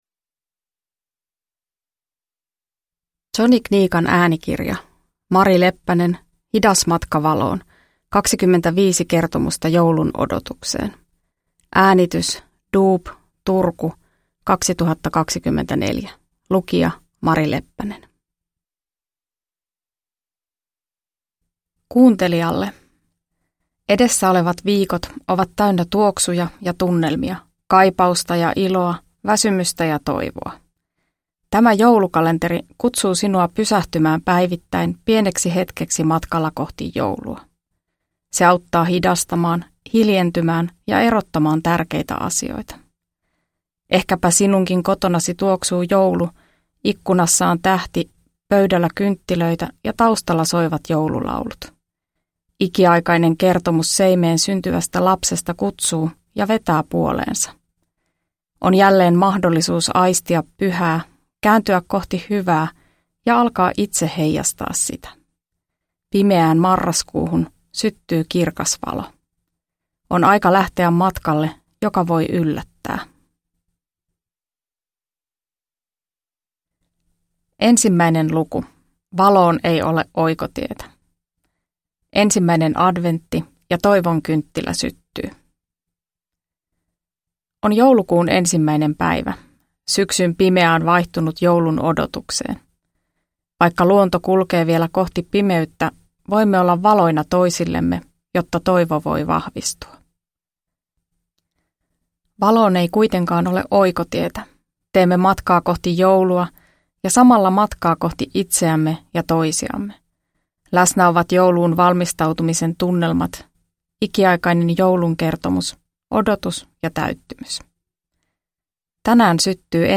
Uppläsare: Mari Leppänen